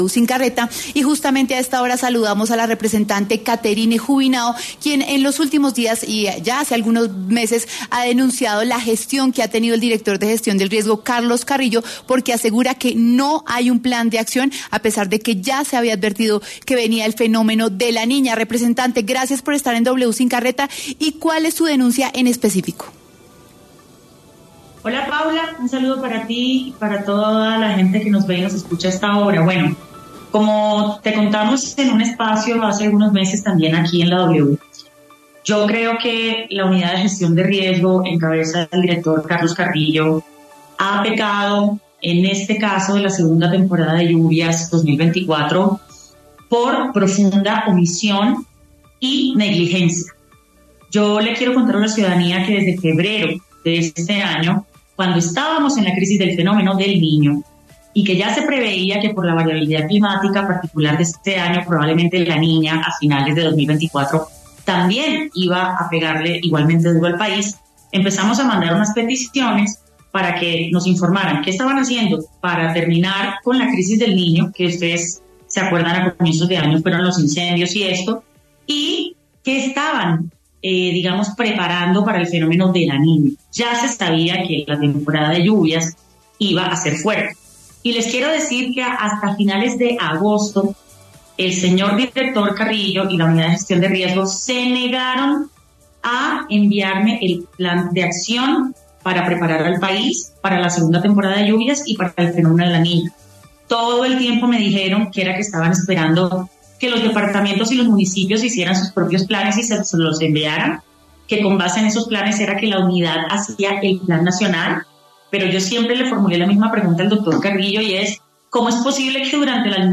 La representante a la Cámara Catherine Juvinao habló con W Sin Carreta a propósito de las intensas lluvias que se han producido en todo el territorio nacional, generando afectaciones especialmente en Chocó, La Guajira y Bogotá.